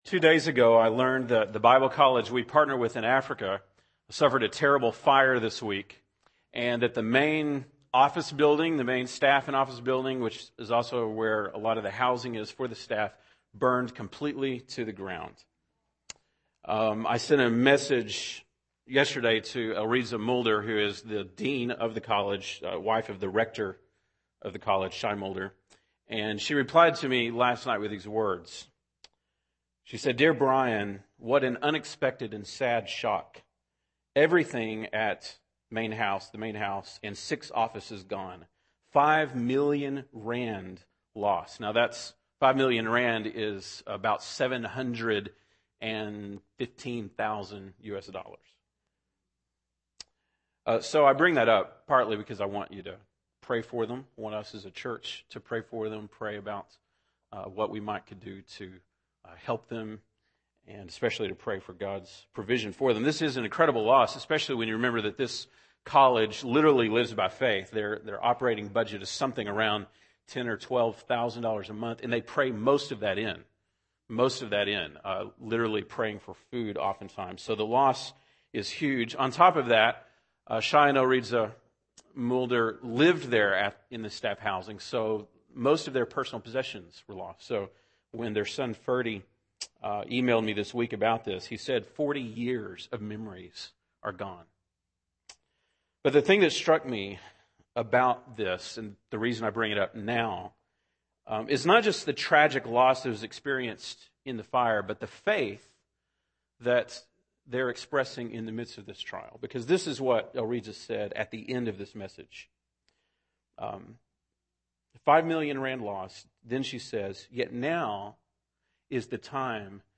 September 26, 2010 (Sunday Morning)